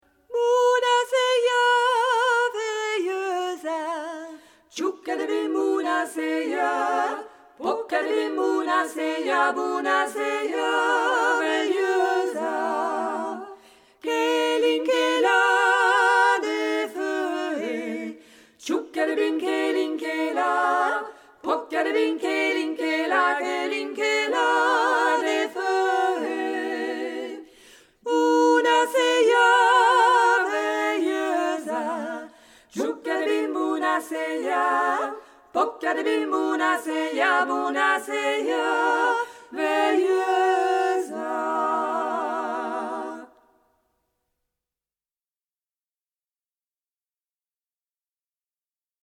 (enregistrement “live”)